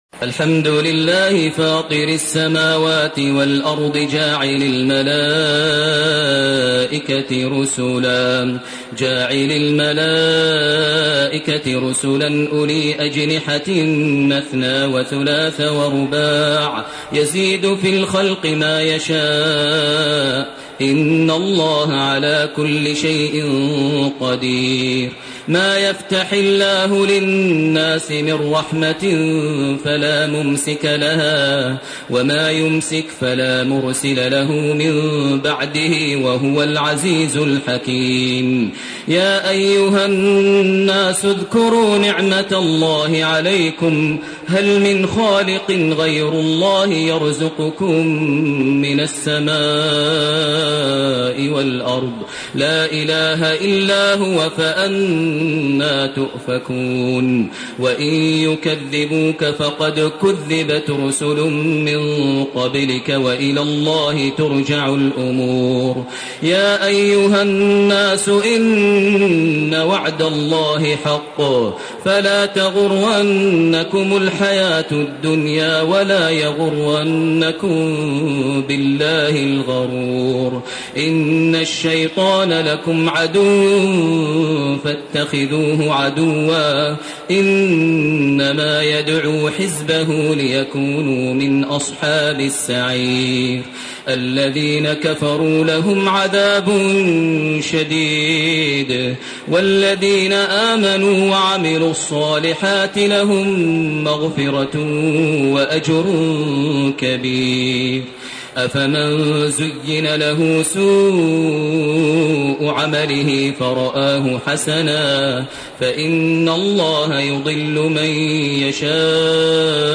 سورة فاطر وسورة يس 1- 36 > تراويح ١٤٣٢ > التراويح - تلاوات ماهر المعيقلي